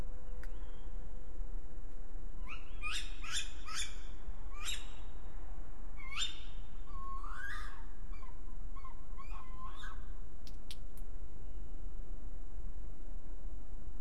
43GE 22/10/23 Data missing 28/10/23 due to clock change. 27/10/23 Tawny Owl